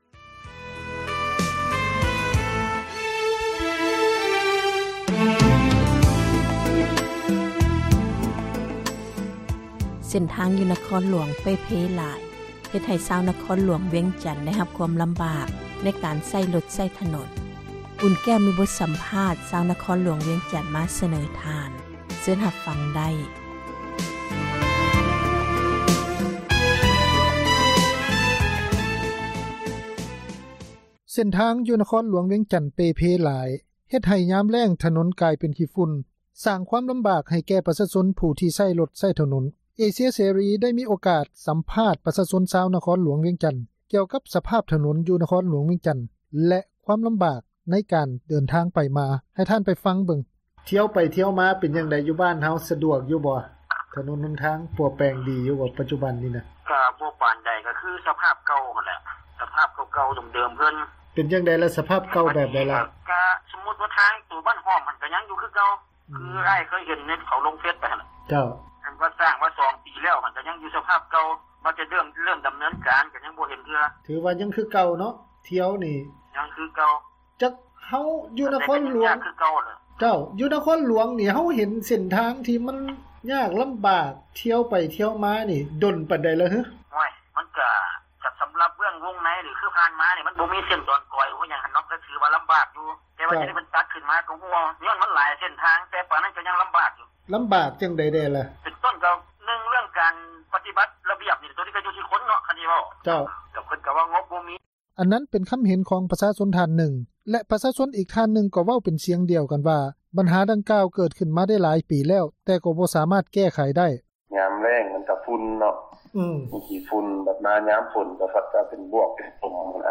ເສັ້ນທາງຢູ່ນະຄອນຫລວງວຽງຈັນ ເປ່ເພຫລາຍ ເຮັດໃຫ້ຍາມແລ້ງ ຖະໜົນກາຍໄປຂີ້ຝຸ່ນ ສ້າງຄວາມລຳບາກ ໃຫ້ແກ່ປະຊາຊົນ ຜູ້ທີ່ໃຊ້ຣົດ ໃຊ້ຖະໜົນ. ເອເຊັຽເສຣີ ໄດ້ມີໂອກາດສຳພາດ ປະທານປະຊາຊົນ ຊາວນະຄອນຫລວງວຽງຈັນ ກ່ຽວກັບ ສະພາບຖະໜົນ ຢູ່ນະຄອນຫລວງ ໃນຕອນນີ້.